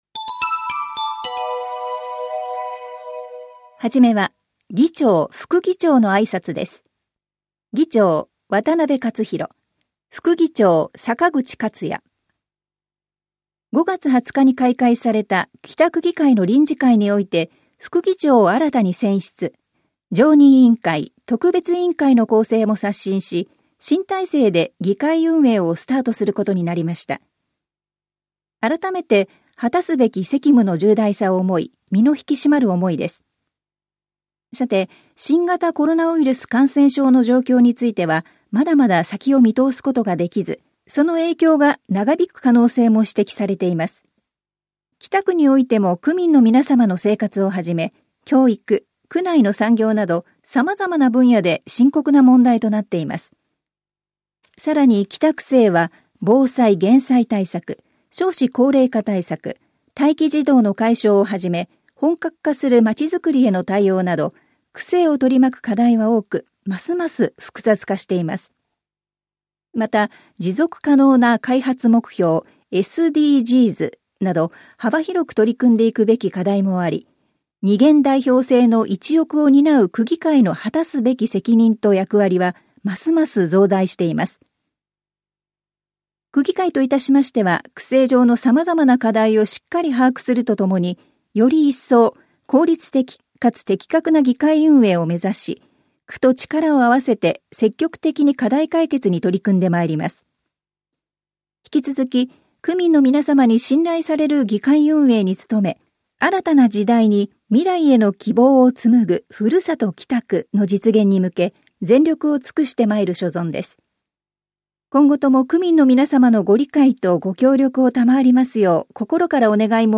音声データは「きたくぎかいだより」の記事を音声化しています。